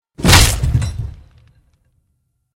Каратист ломает доски рукой или ногой